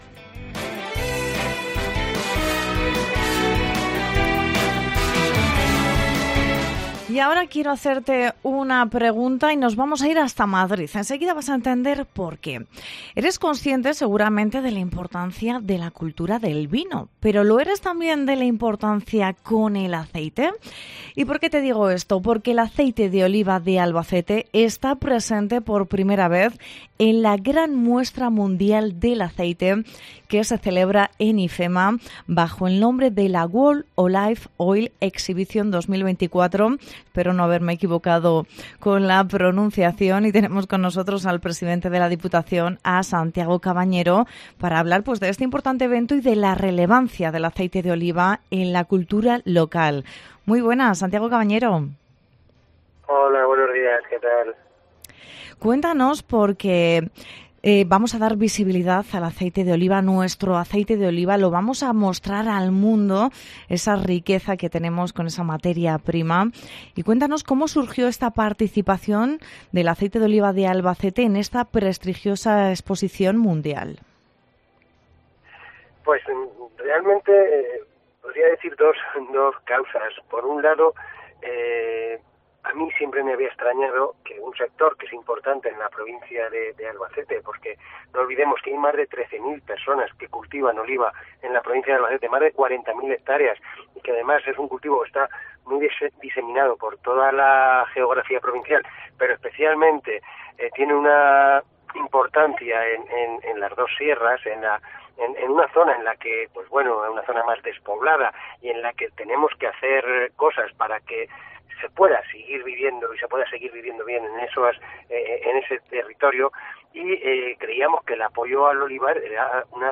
Entrevista con Santiago Cabañero, presidente de la Diputación de Albacete